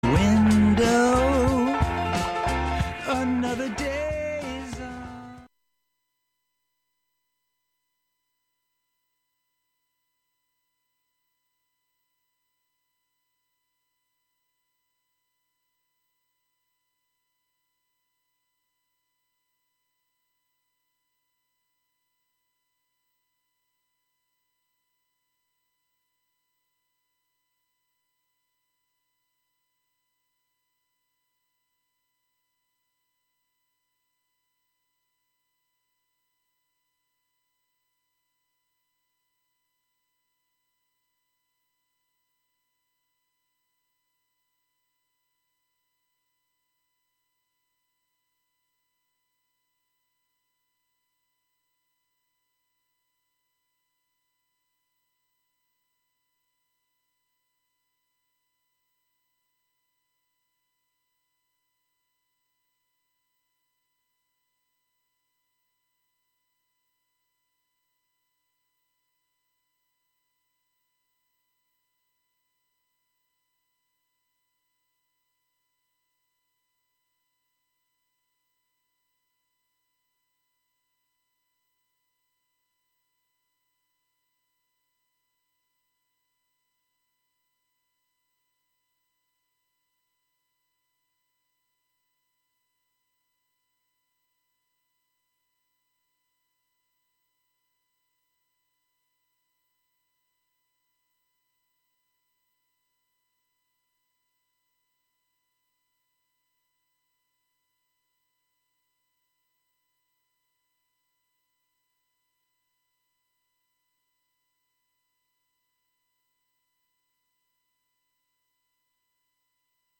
I like to start them out at 125 bpm and just keep building.